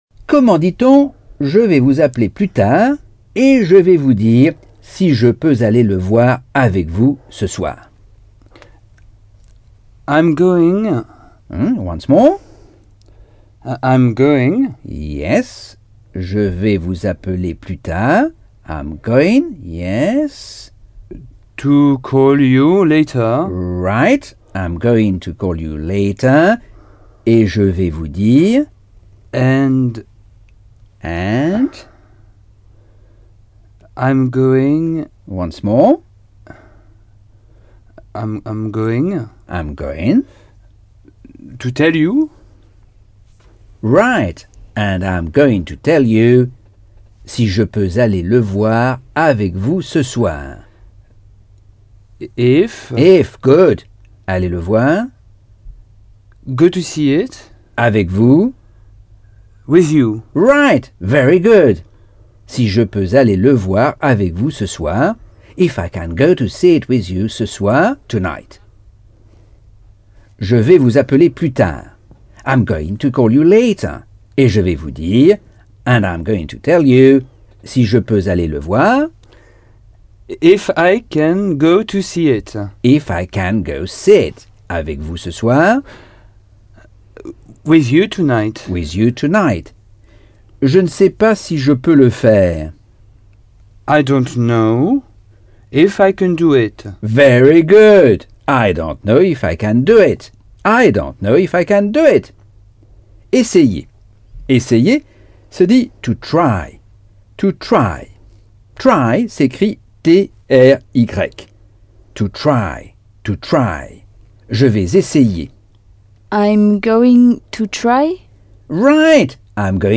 Leçon 10 - Cours audio Anglais par Michel Thomas